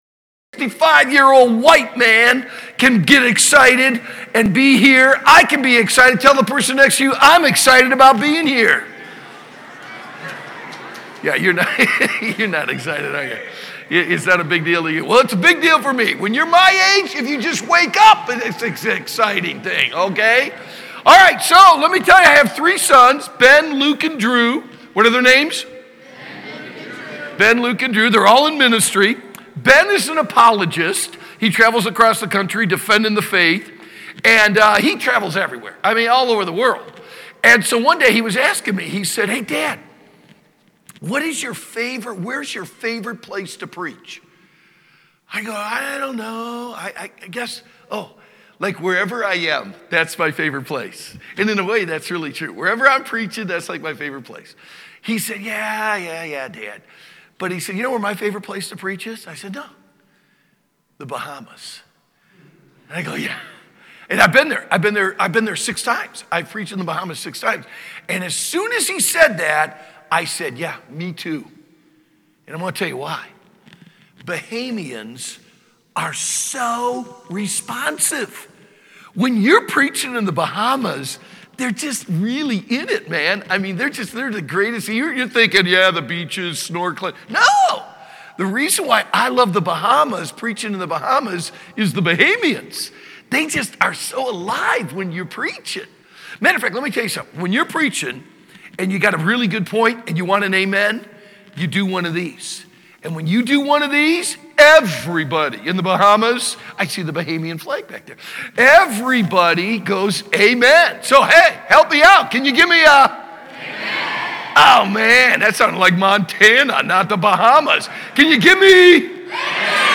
Renew Youth Conference Session 1 – Bible Baptist Church